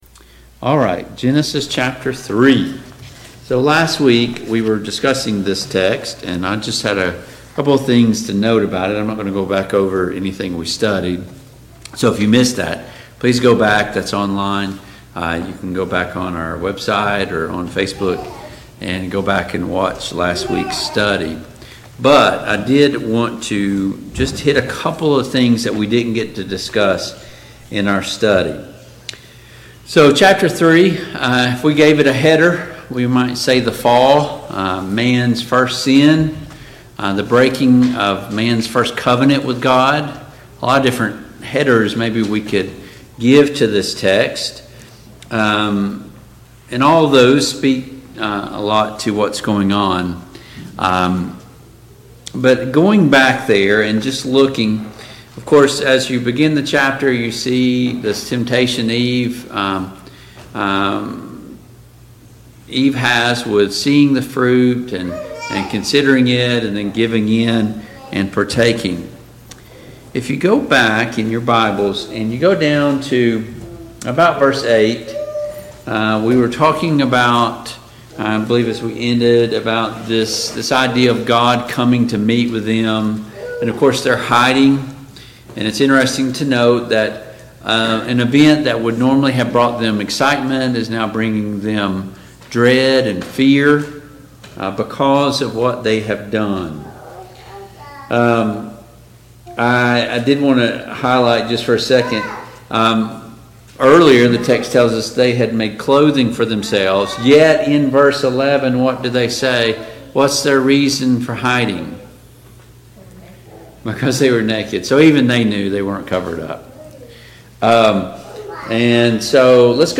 Genesis 4 Service Type: Family Bible Hour Topics: The Fall « 5.